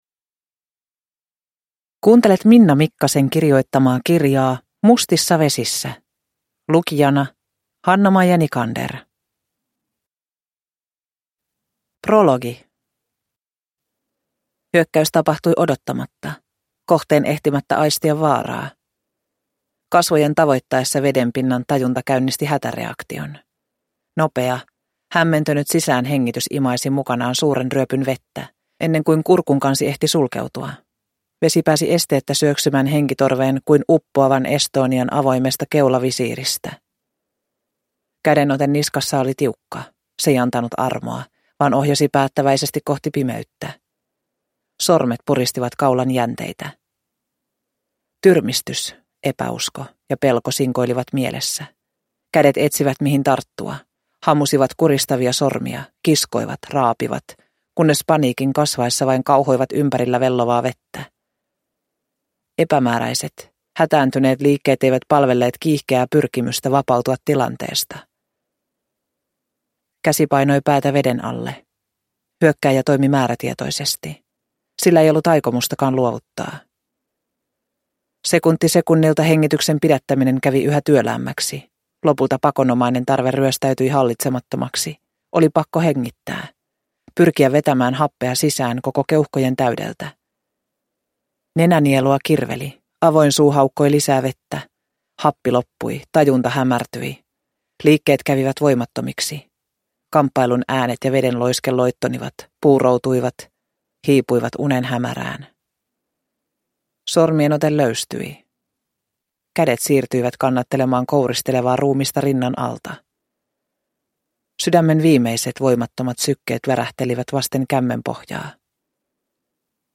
Mustissa vesissä – Ljudbok – Laddas ner